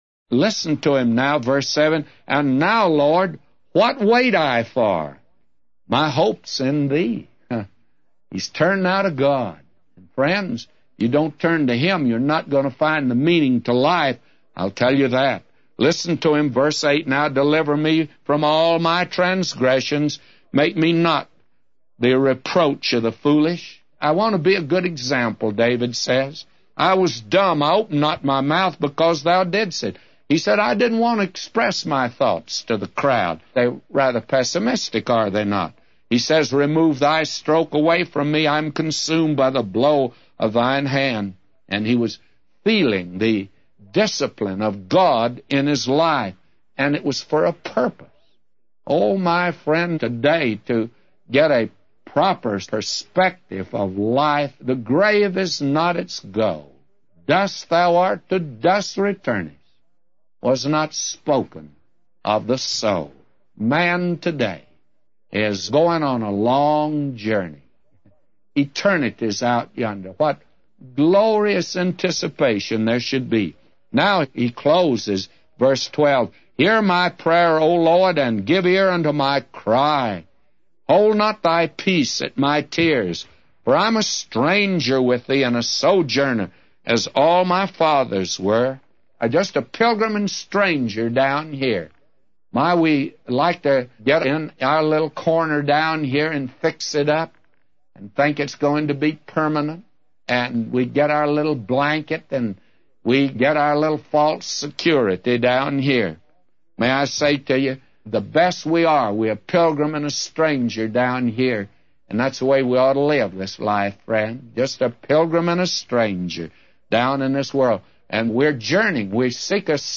A Commentary By J Vernon MCgee For Psalms 39:7-999